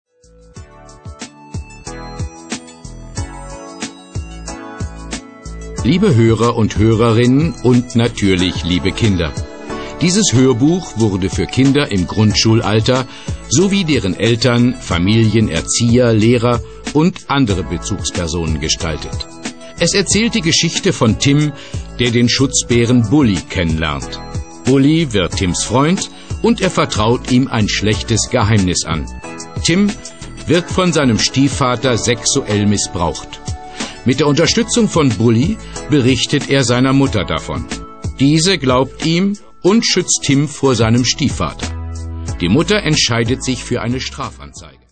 Das Hörbuch "Schutzbär Bulli", CD zum Buch